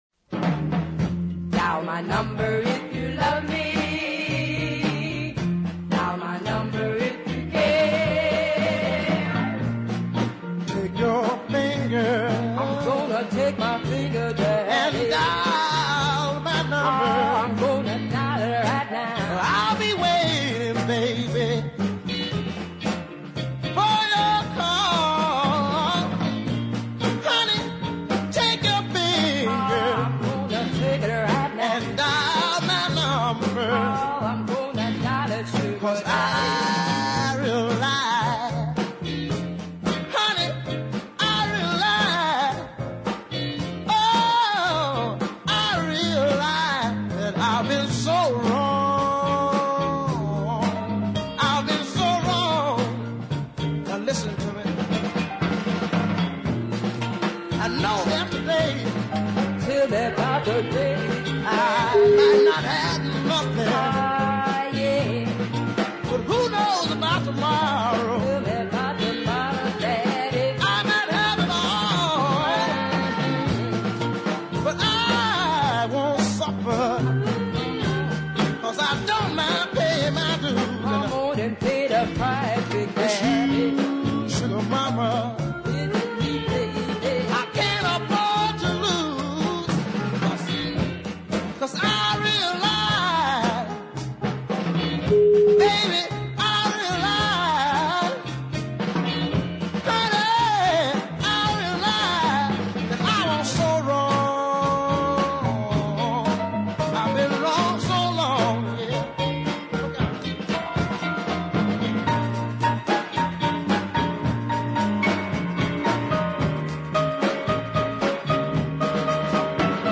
mid-paced bluesy beat ballad
fiery, hoarse tones